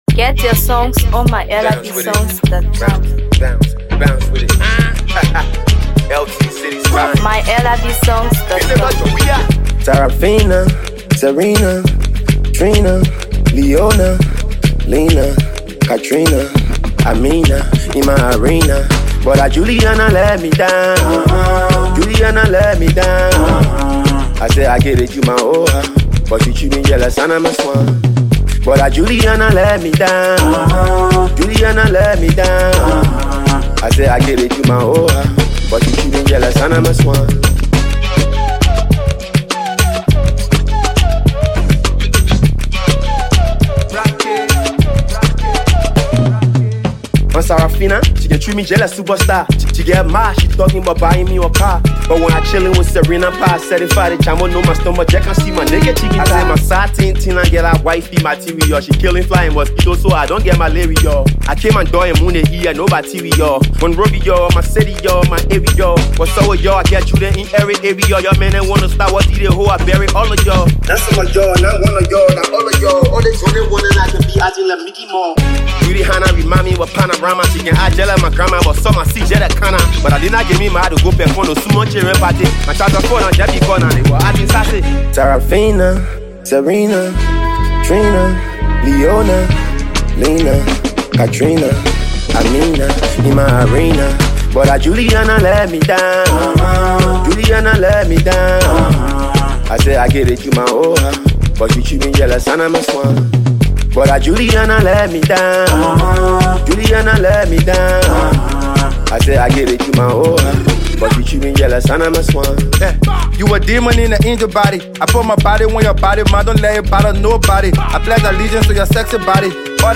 blending Hipco, Hip-hop, and Afro-fusion sounds.